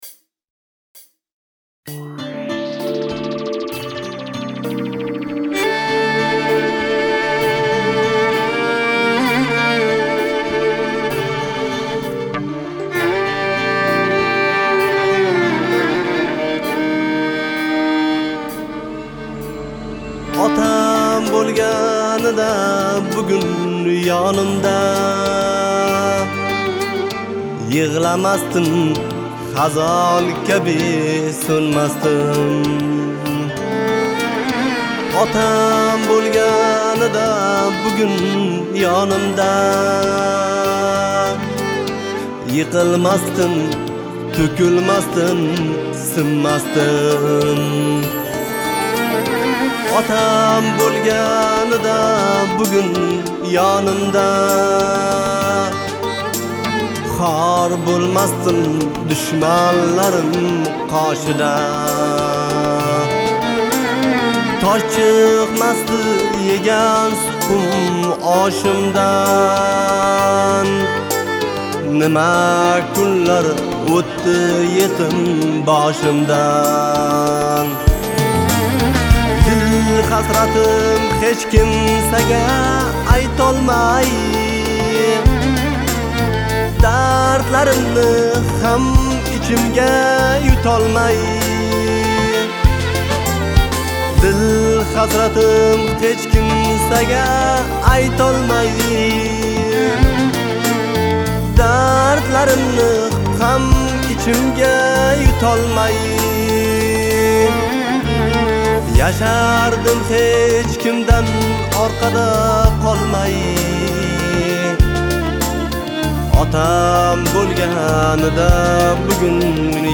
Узбекская песня